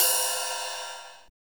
LINN RIDE.wav